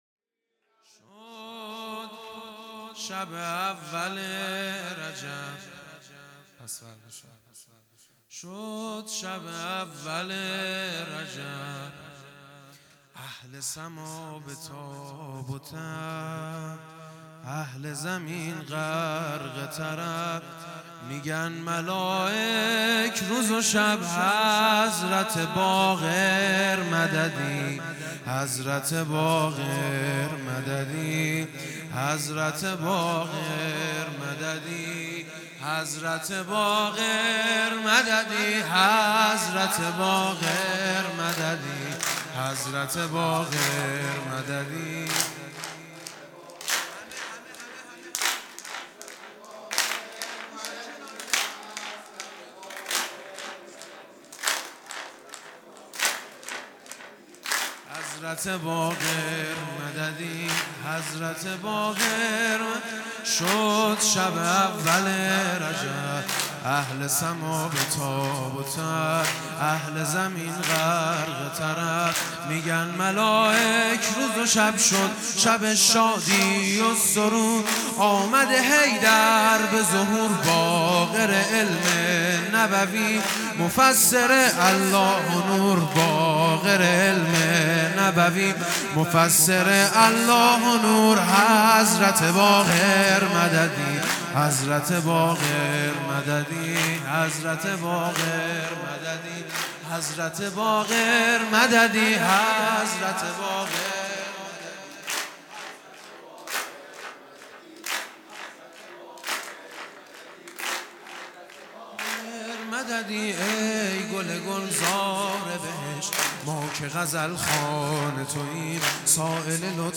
سرود
هیئت دانشجویی فاطمیون دانشگاه یزد